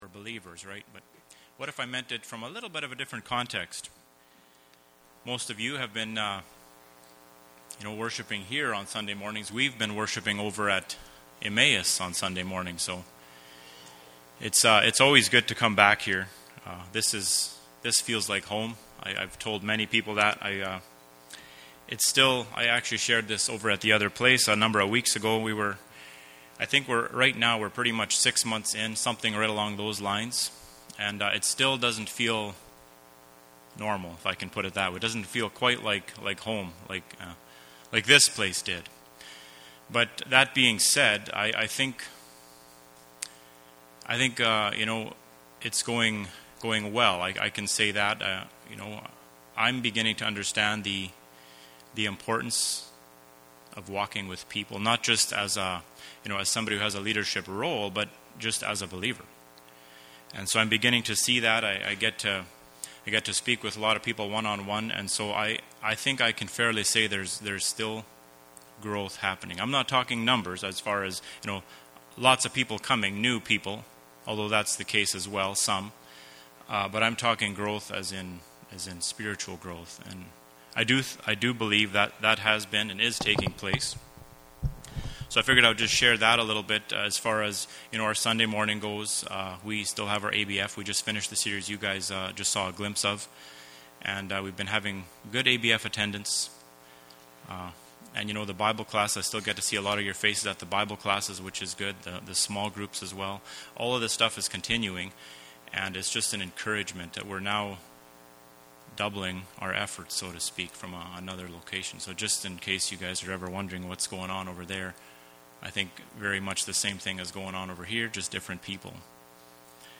Passage: Proverbs 3:27-35 Service Type: Sunday Morning